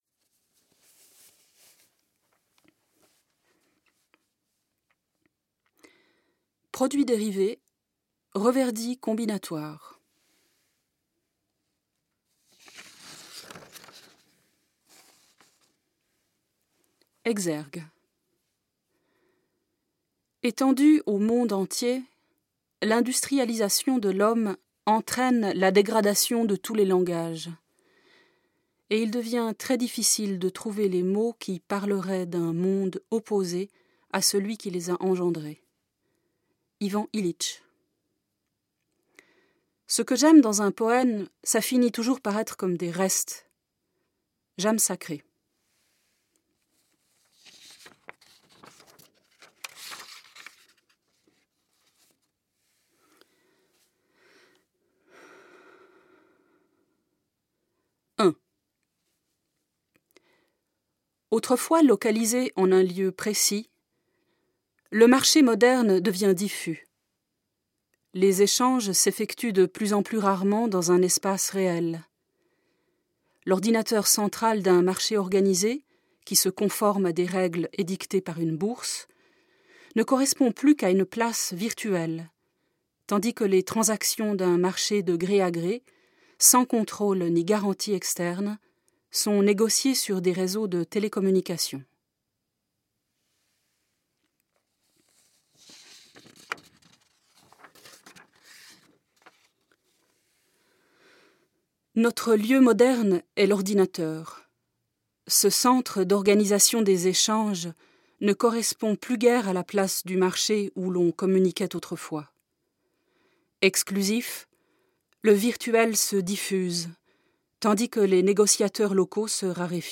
Lecture chantée du livre